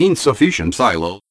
LOL, combining Psy and Low, nice trick!